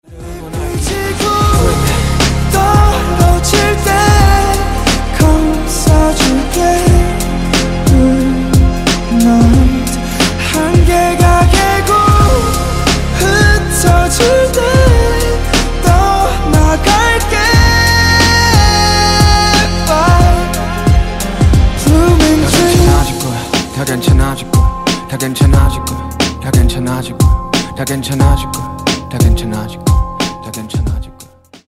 K-hip-hop